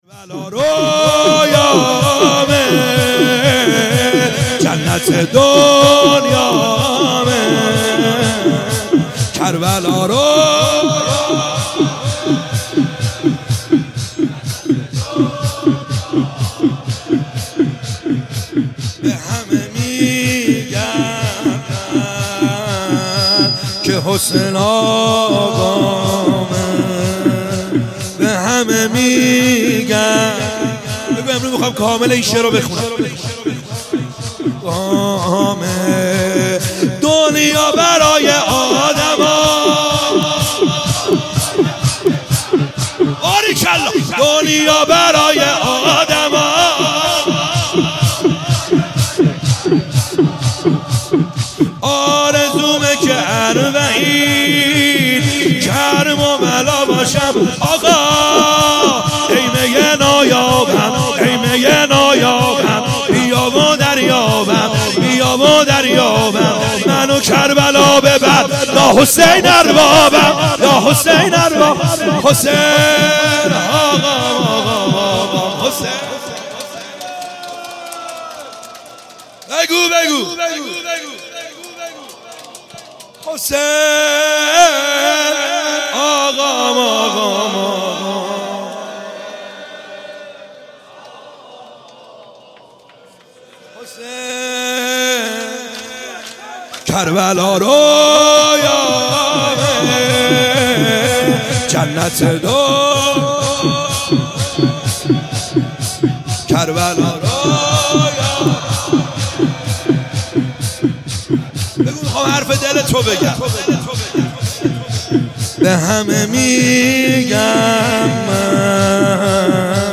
خیمه گاه - بیرق معظم محبین حضرت صاحب الزمان(عج) - شور | کربلا رویامه جنت دنیامه